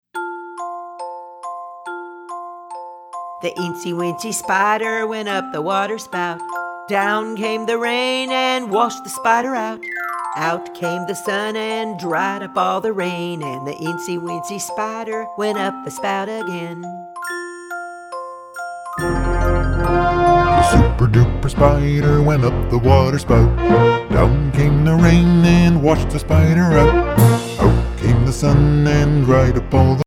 hand clappin', foot stompin' fun for everyone!